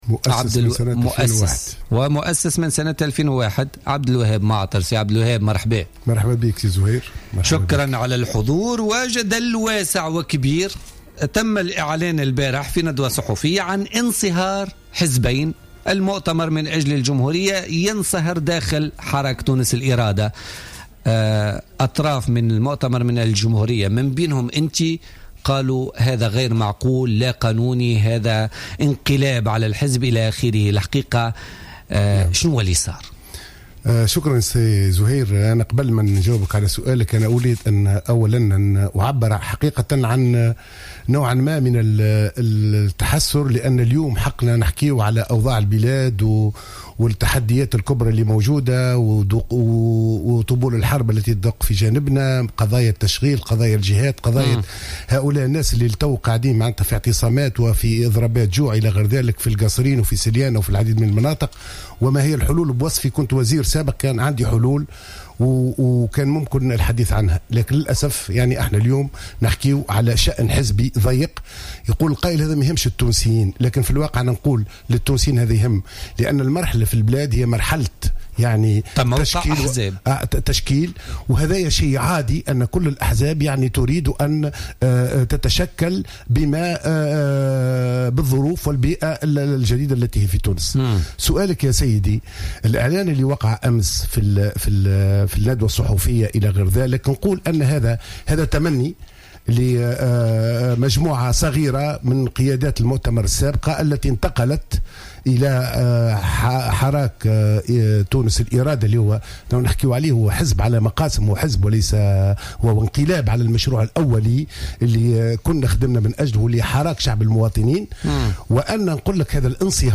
أكد القيادي في حزب المؤتمر من أجل الجمهورية عبد الوهاب معطر ضيف بوليتيكا اليوم الثلاثاء 16 فيفري 2016 أن الإعلان عن دمج حزب المؤتمر من اجل الجمهورية مع حزب حراك تونس الإرادة الذي تم يوم أمس هو عملية سطو على الحزب على حد قوله.